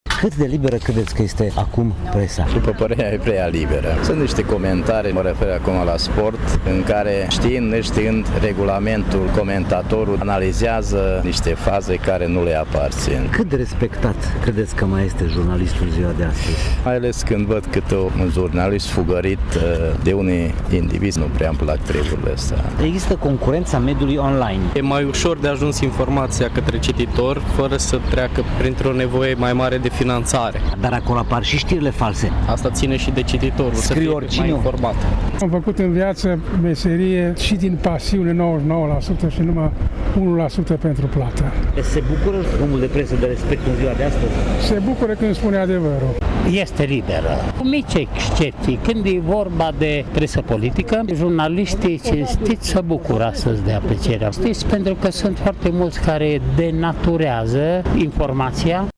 Aceasta este părerea cetățenilor și a unor oameni de presă din Tîrgu-Mureș, surprinsă astăzi, cu prilejul Zilei Mondiale a Libertăţii Presei.